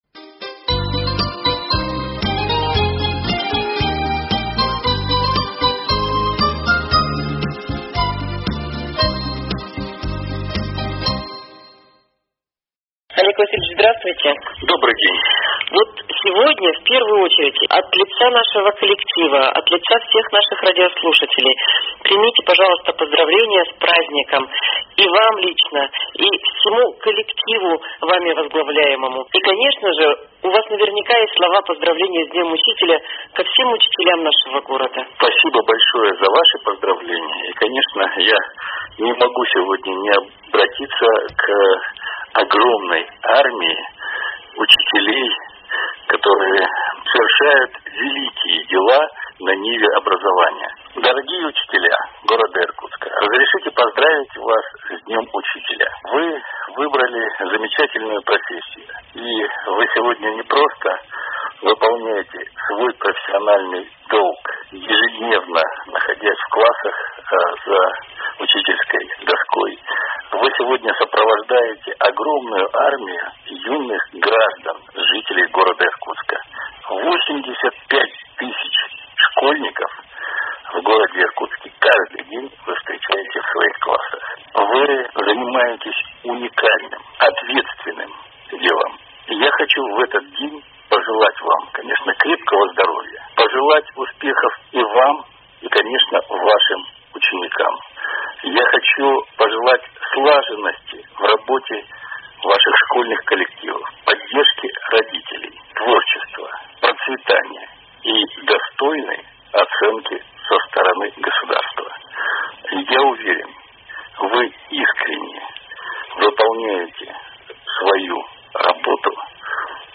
Поздравление ко Дню Учителя от начальника департамента образования Комитета по социальной политике и культуре администрации г. Иркутска Олега Васильевича Ивкина. Беседовала с ним по телефону